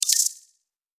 Epic Holographic User Interface Click 8.wav